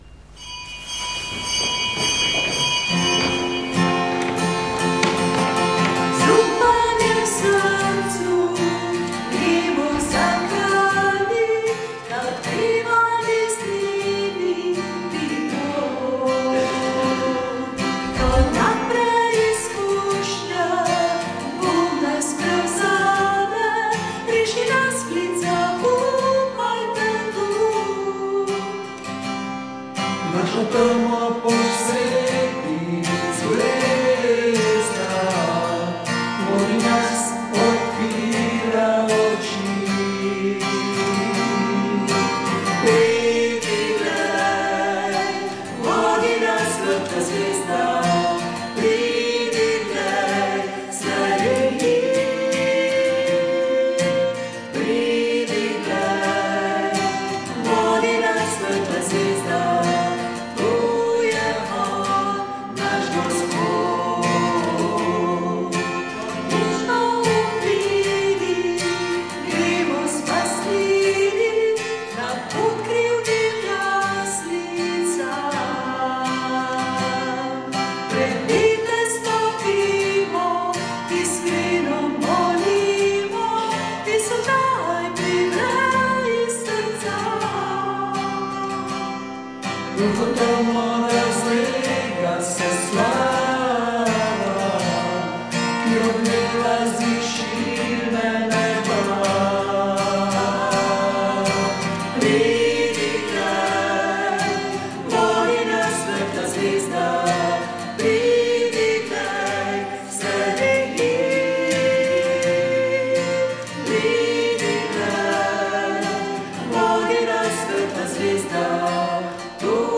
Sv. maša iz cerkve sv. Marka na Markovcu v Kopru 8. 1.